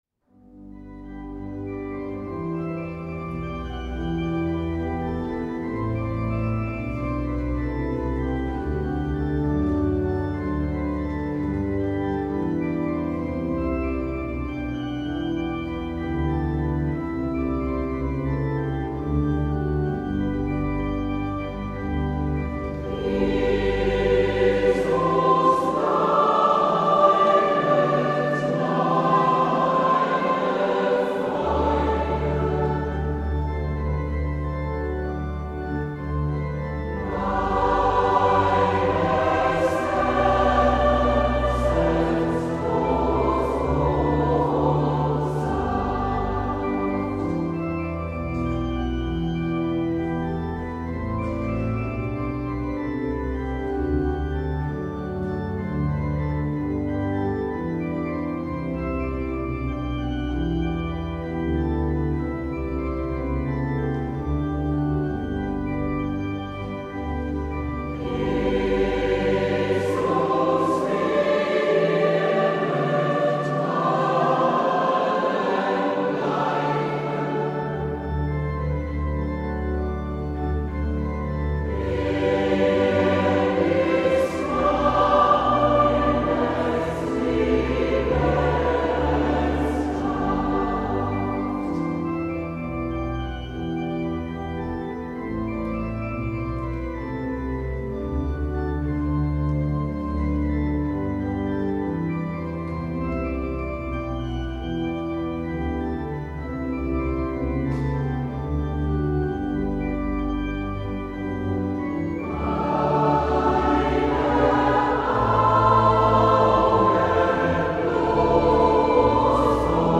Kirchenchor
In unserem Kirchenchor singen ca. 30 Sängerinnen und Sänger.